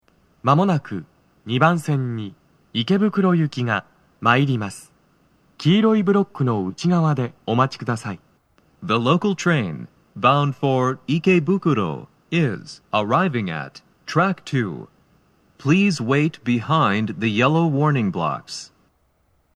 スピーカー種類 TOA天井型()
🎵接近放送
鳴動は、やや遅めです。
男声